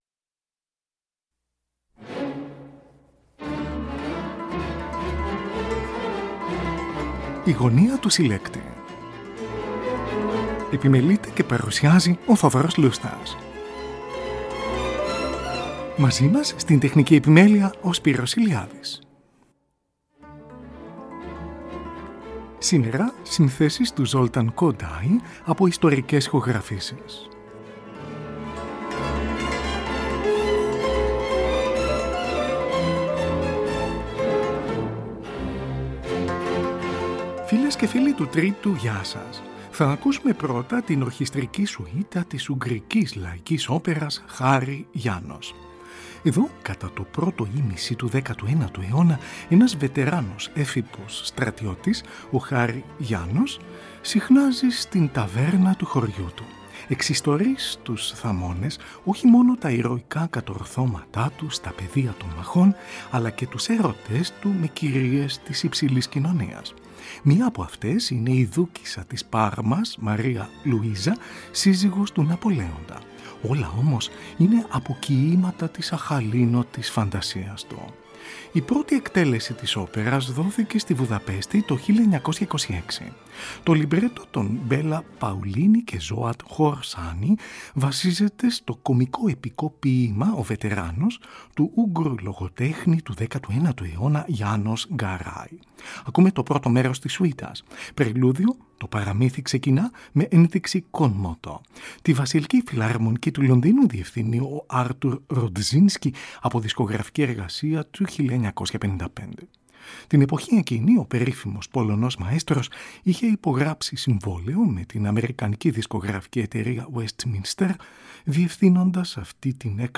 ΙΣΤΟΡΙΚΕΣ ΗΧΟΓΡΑΦΗΣΕΙΣ ΕΡΓΩΝ
Oρχηστρική σουίτα
στην εκδοχή για χορωδία και εκκλησιαστικό όργανο.